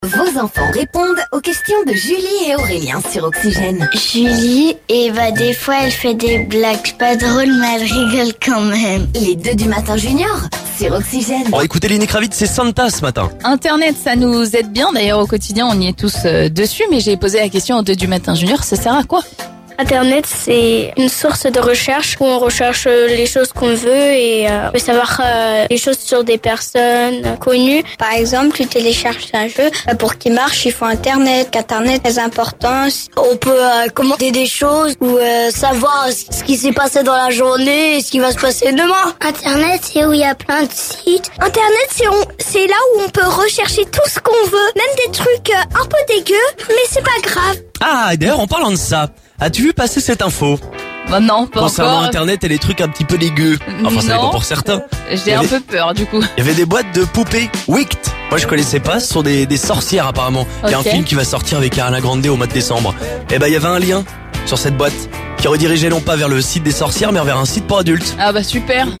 aux enfants seine et marnais de nous expliquer à quoi servait Internet...